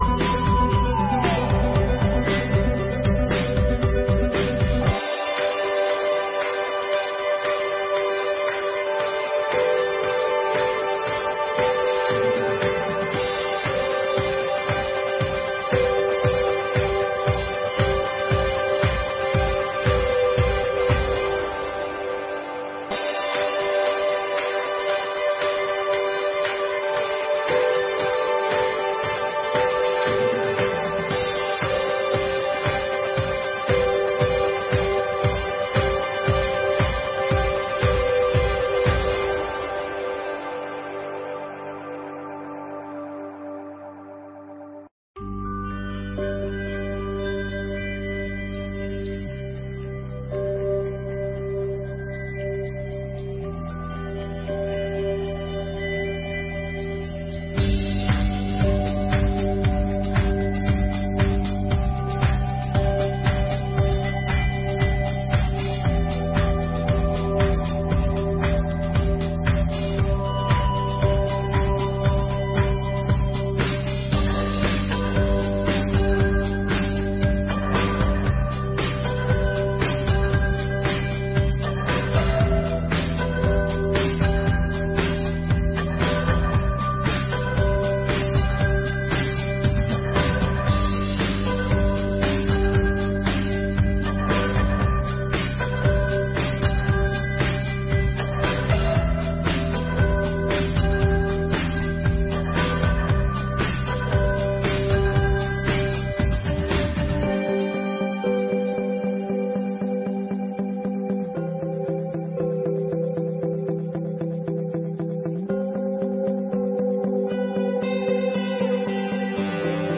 1ª Sessão Ordinária de 2024